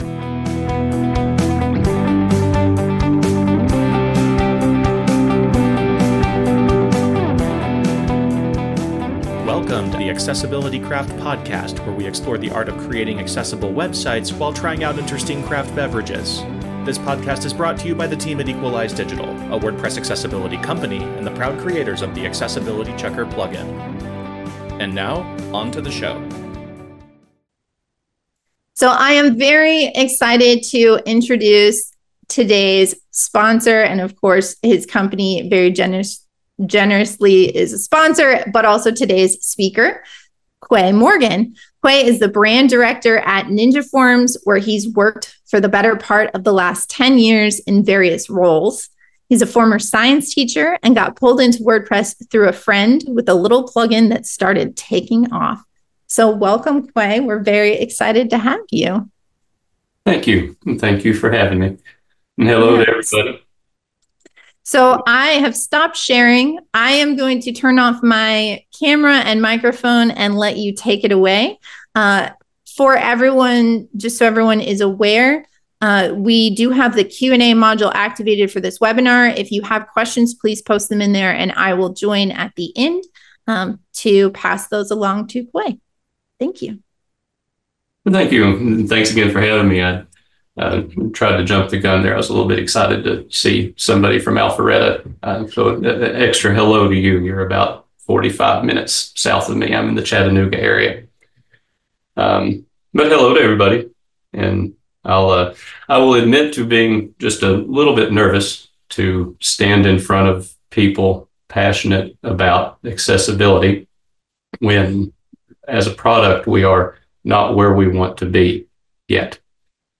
This episode is a recording of a September 2024 WordPress Accessibility Meetup